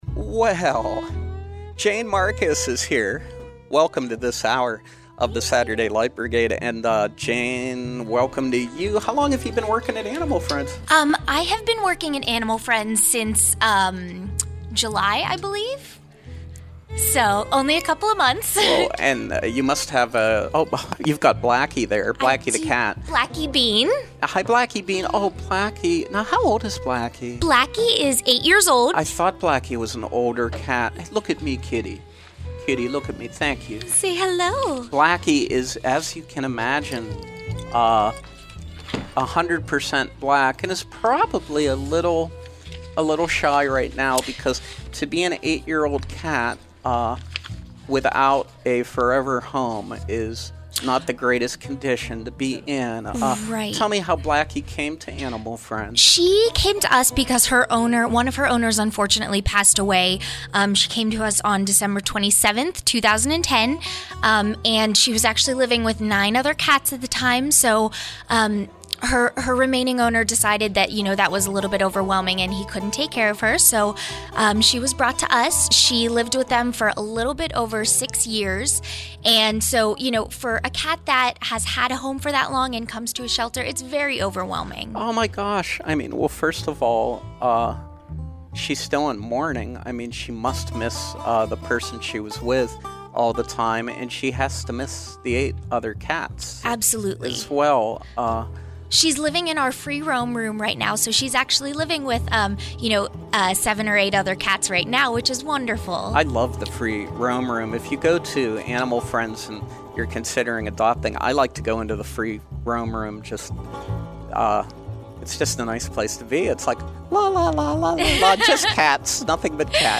Home » Conversation, Featured, Interviews